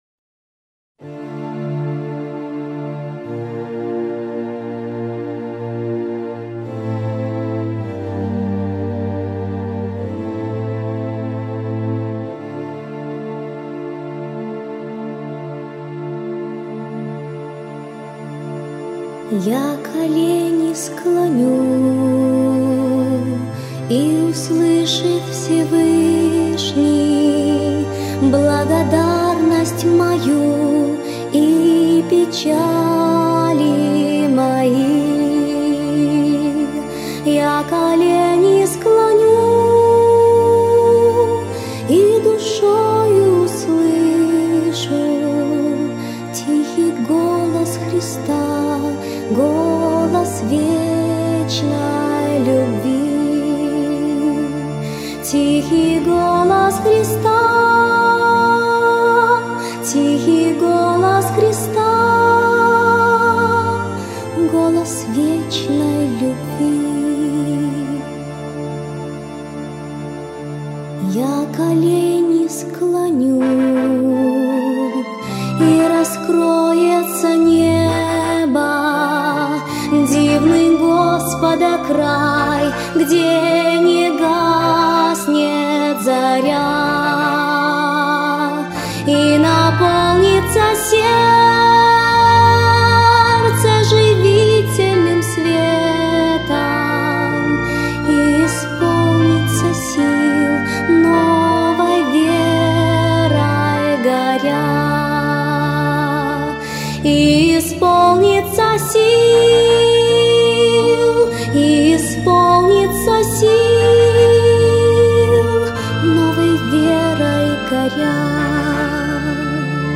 on 2015-12-27 - Молитвенное пение